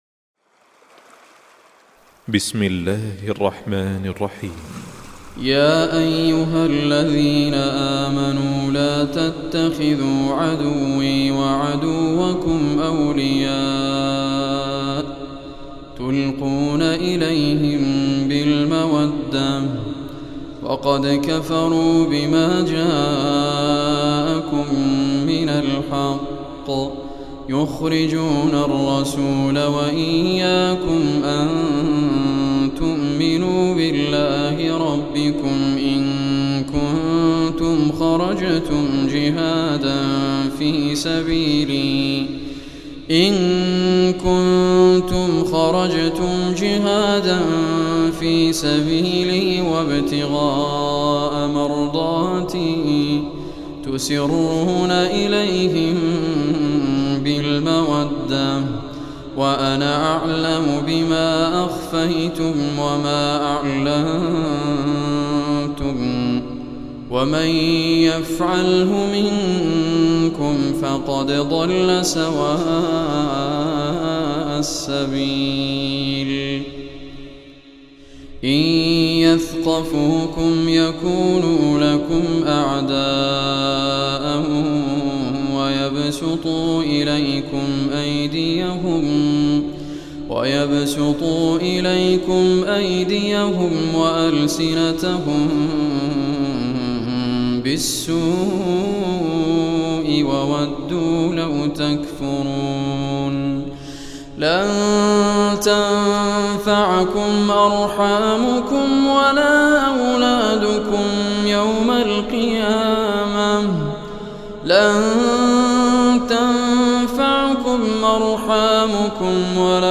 Surah Al-Mumtahanah Recitation by Raad Kurdi
Surah Al-Mumtahanah, is 60th chapter of Holy Quran. Listen or play online mp3 tilawat / recitation in arabic in the beautiful voice of Sheikh Raad Muhammad Al Kurdi.